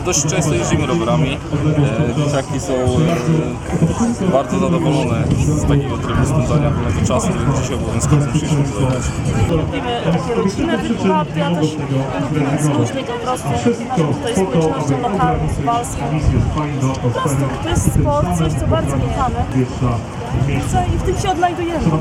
Jak przyznali w rozmowie z Radiem 5, jazda na rowerze to samo zdrowie.